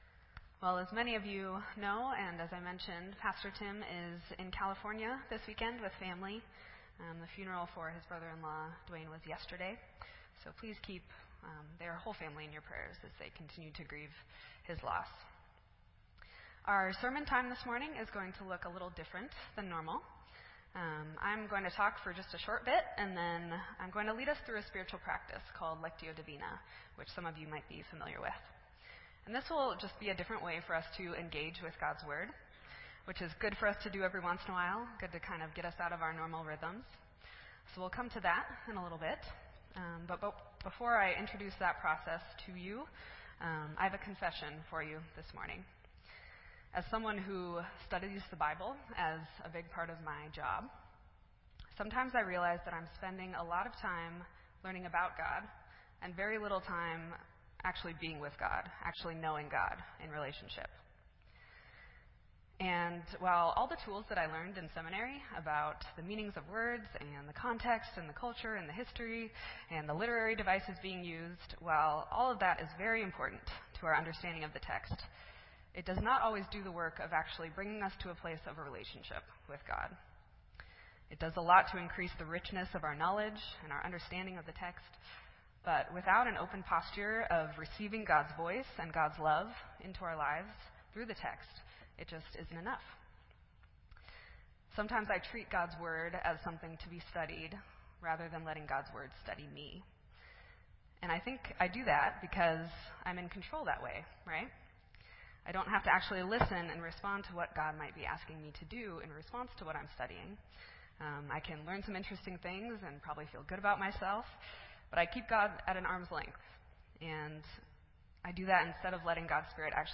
This entry was posted in Sermon Audio on February 12